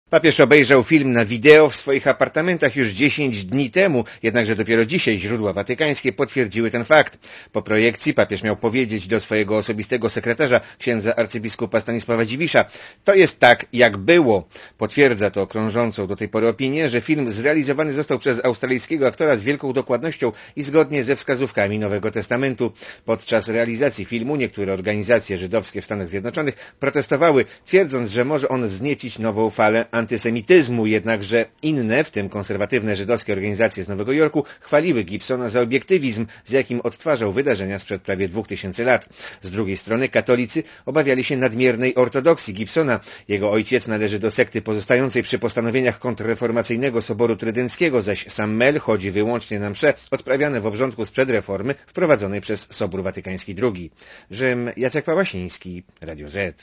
Komentarz audio (224kB)